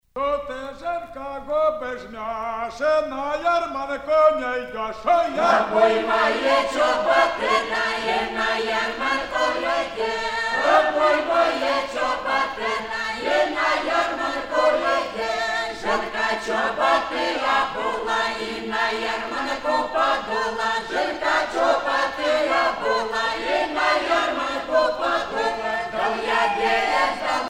Dance song
Russian folksongs sung by the Don Cossacks